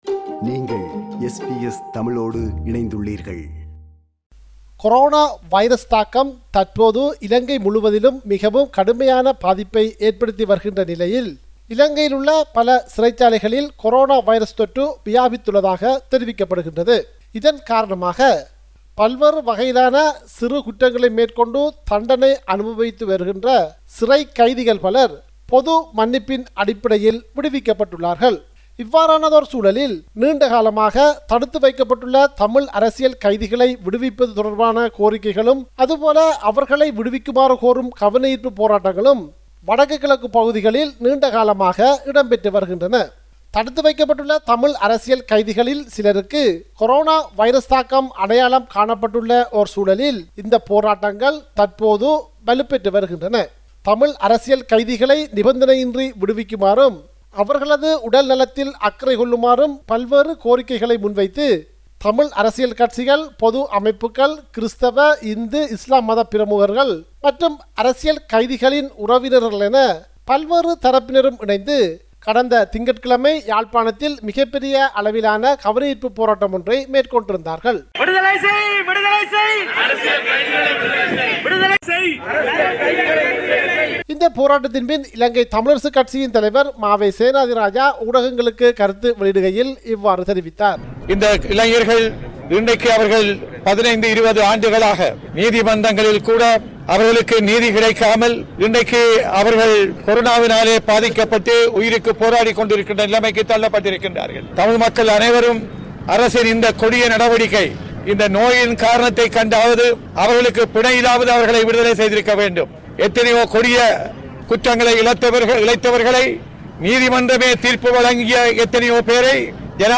Australian news bulletin for Friday 01 January 2021.